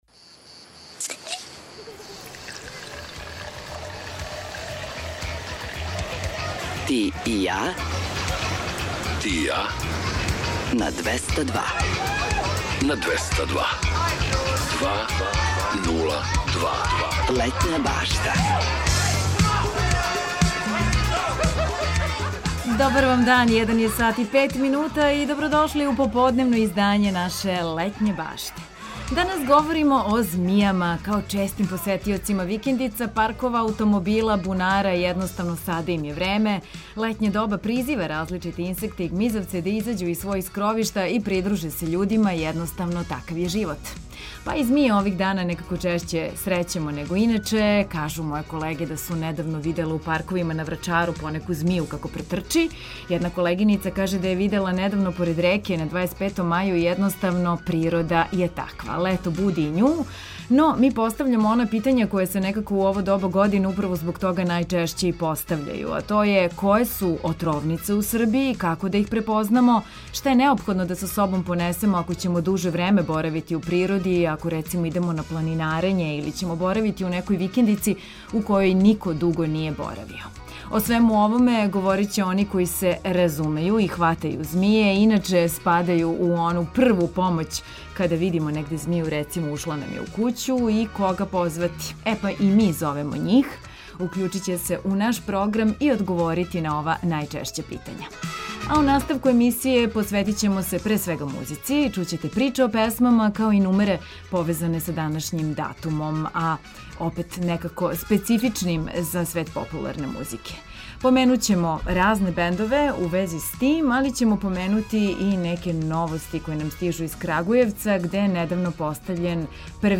У наставку емисије посвећујемо се музици, пре свега. Чућете „Приче о песмама” као и нумере повезане са данашњим датумом.